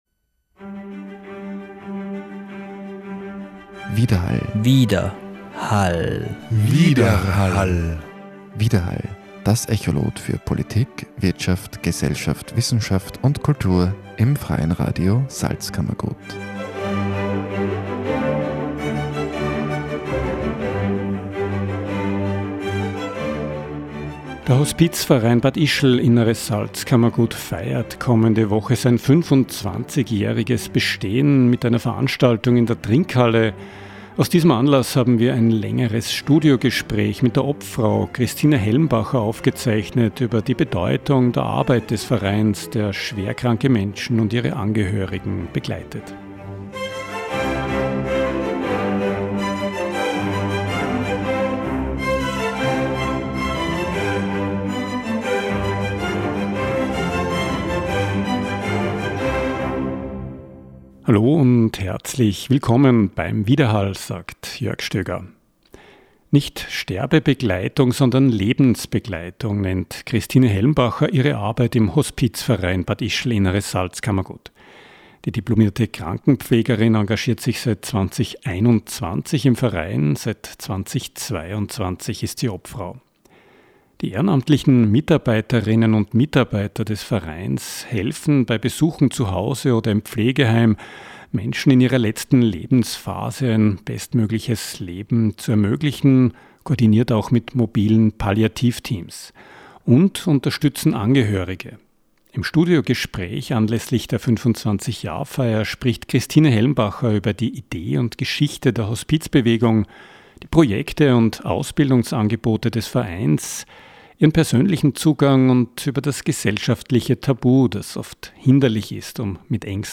25 Jahre Hospizverein Bad Ischl – Inneres Salzkammergut Studiogespräch